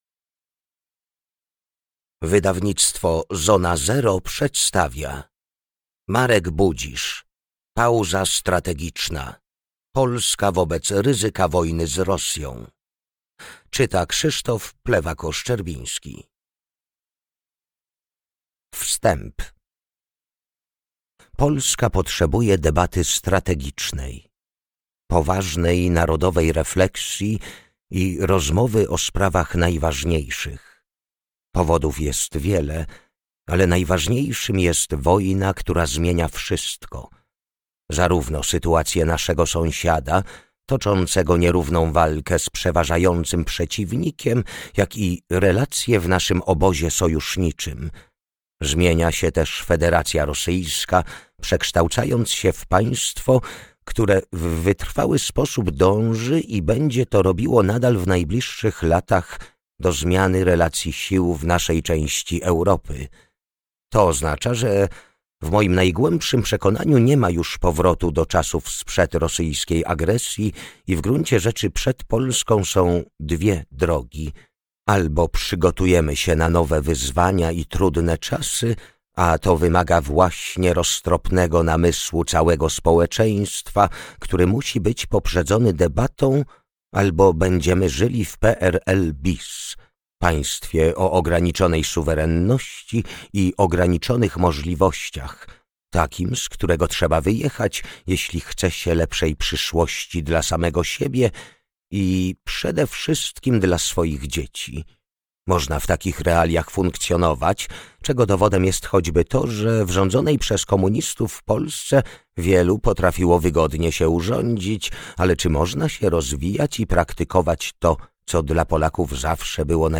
Pauza strategiczna – Audiobook